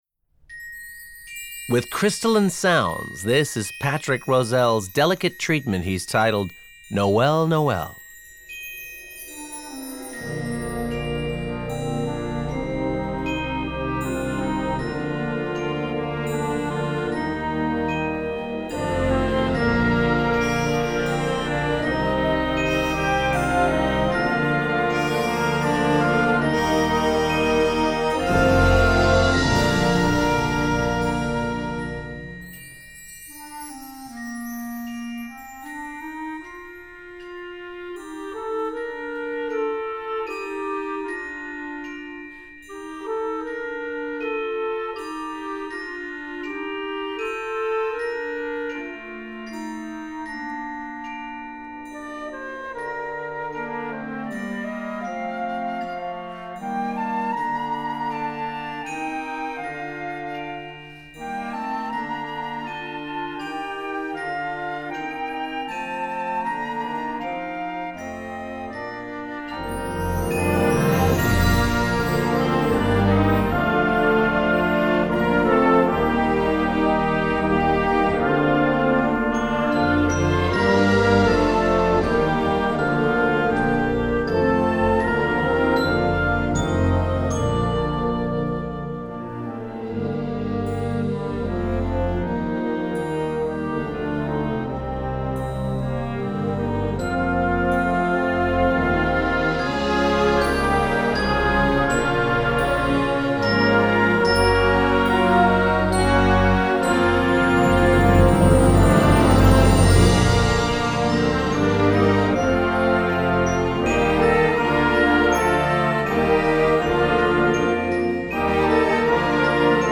Besetzung: Blasorchester
Beautiful and lush